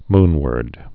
(mnwərd)